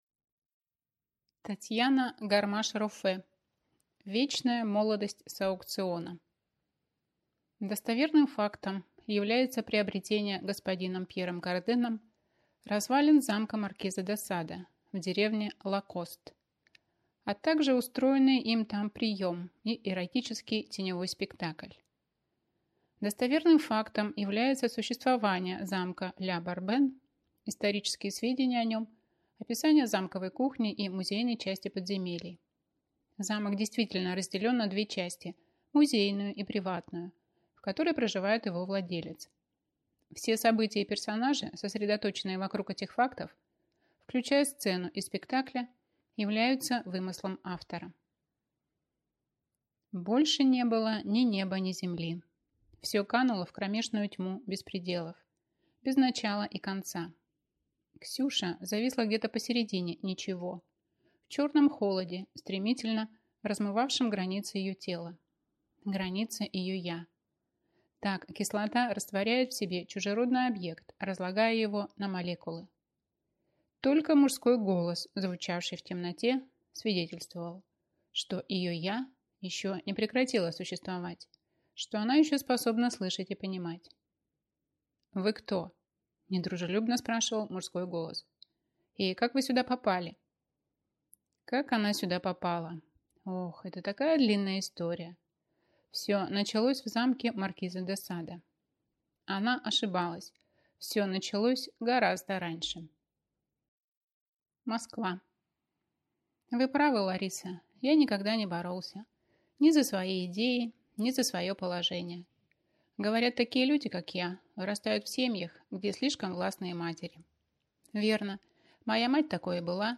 Аудиокнига Вечная молодость с аукциона | Библиотека аудиокниг